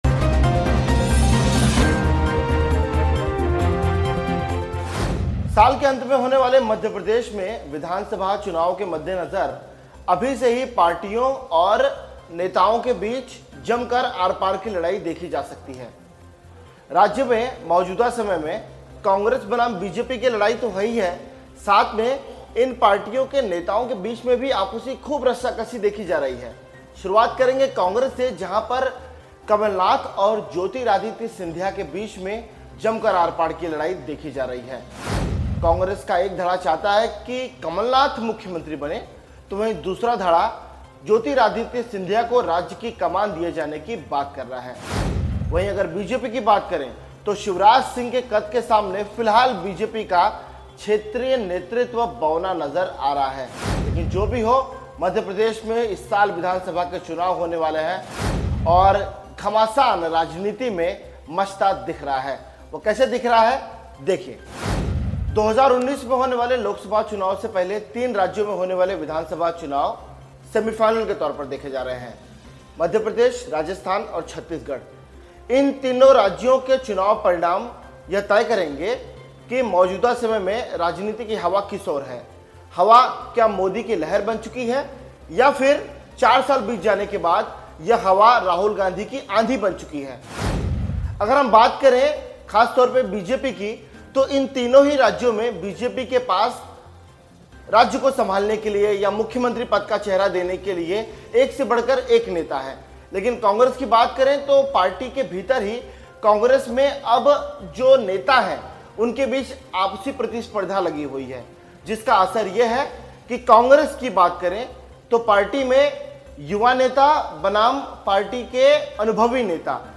न्यूज़ रिपोर्ट - News Report Hindi / मध्यप्रदेश में विधानसभा चुनाव है, सीएम बनने के लिए कांग्रेस के कमलनाथ-सिंधिया में कलह शुरू!